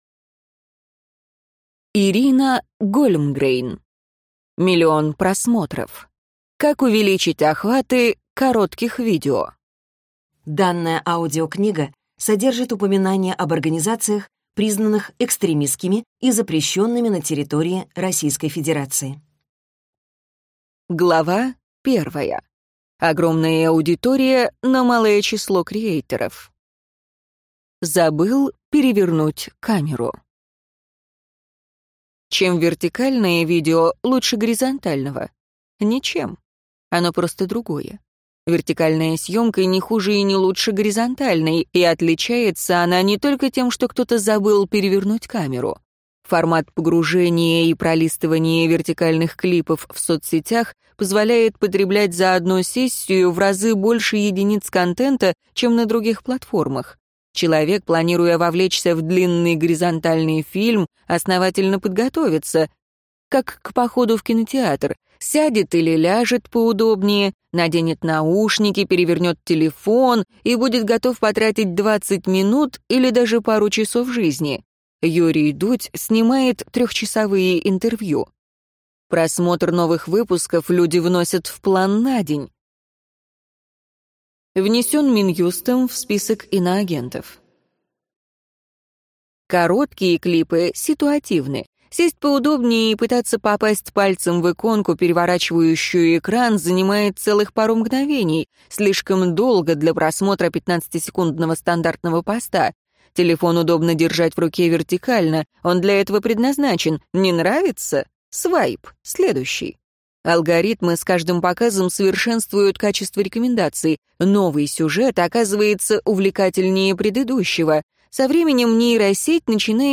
Аудиокнига Миллион просмотров. Как увеличить охваты коротких видео | Библиотека аудиокниг